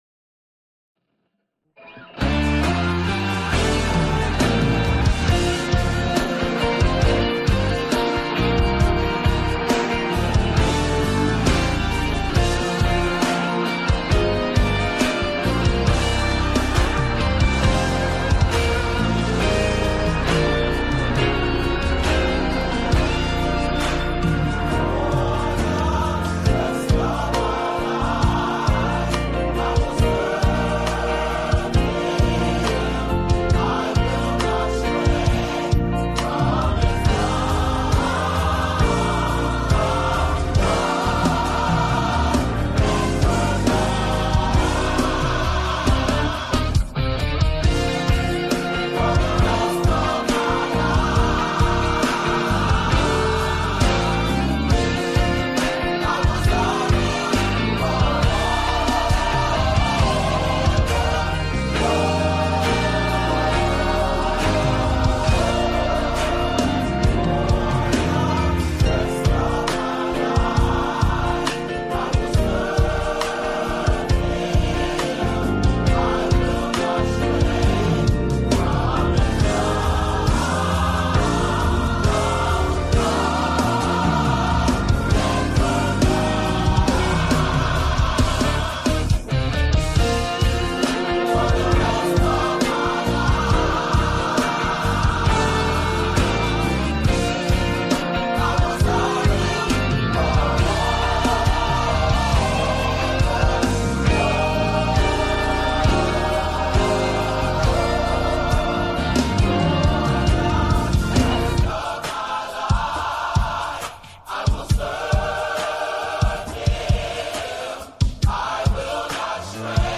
Music Ministry – Women’s Day 2025 – Beth-El Temple Church
For-The-Rest-Of-My-Life-VB3910-Youth-Choir.mp3